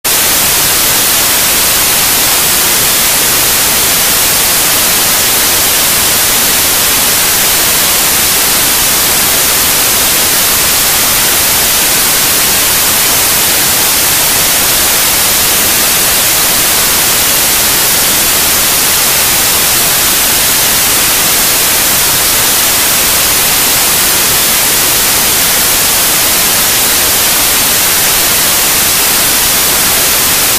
A l’aide du fichier du bruit blanc :
Bruit-blanc.mp3